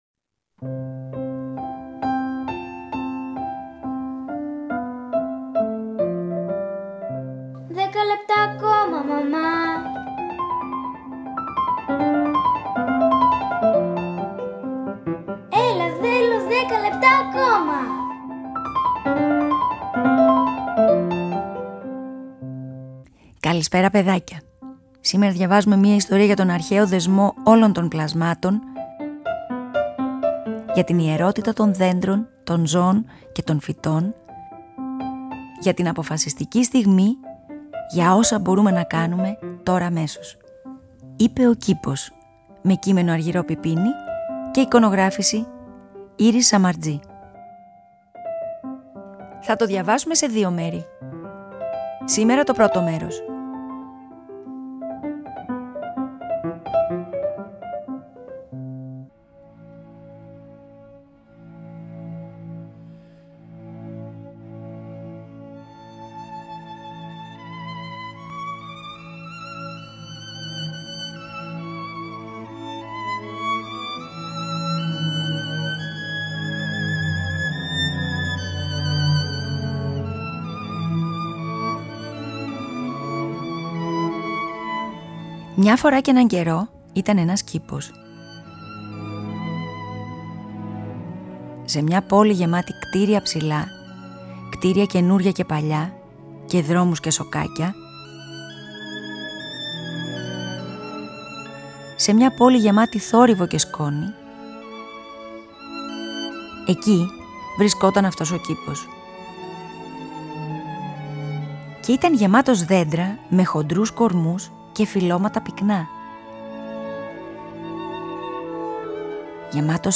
Θα το διαβάσουμε σε δύο μέρη.